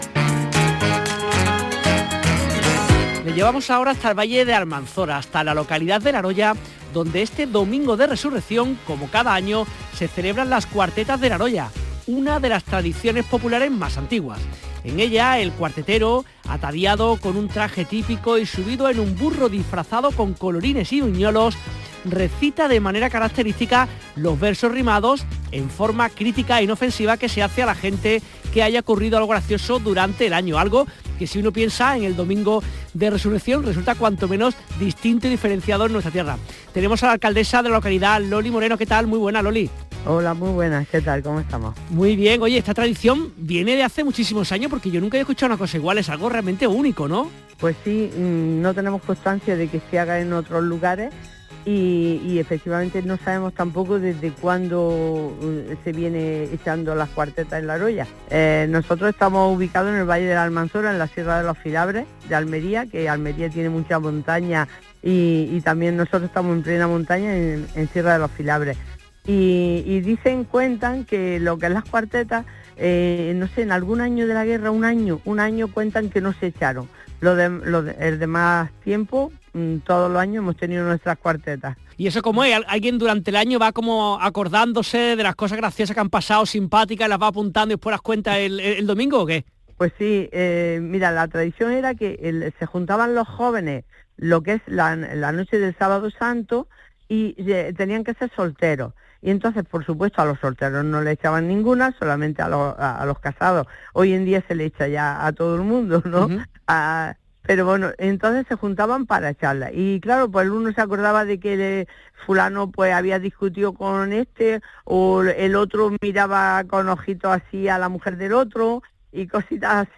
Corte del programa dedicado a Laroya: